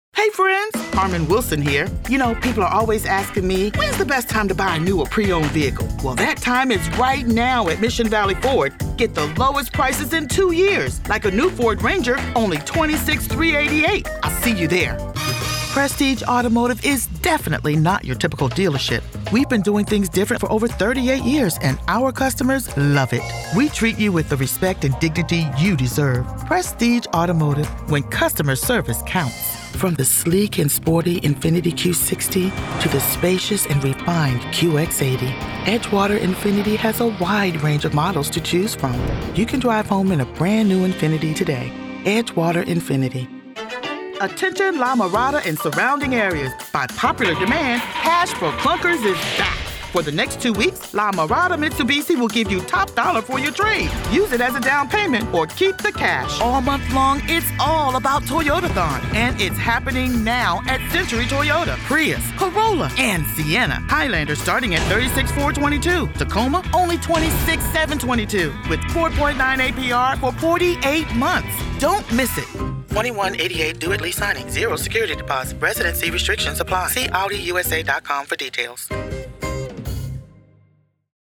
A voice confident enough to command a room and inviting enough to make anyone feel like they’re the only one listening.